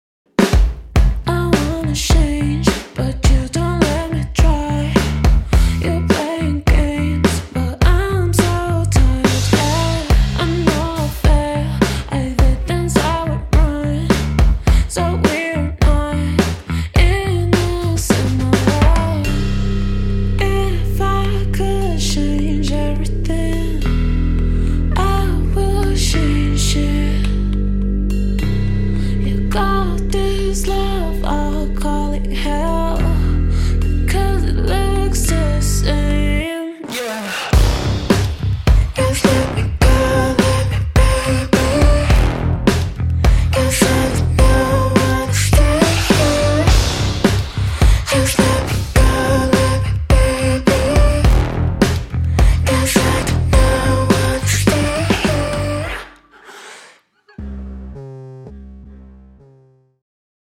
• 4 Acapella Vocals Dry
• 32 Instrumental Loops
• 38 Drum Loops